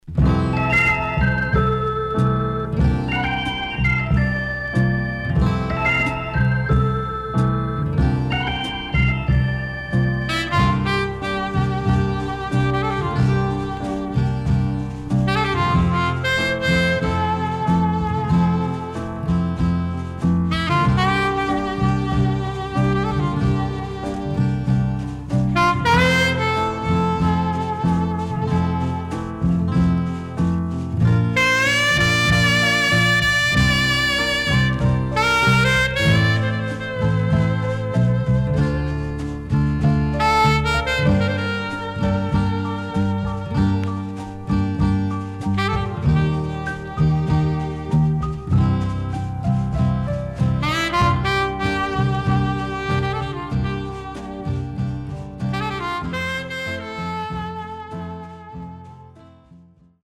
SIDE A:少しノイズ入ります。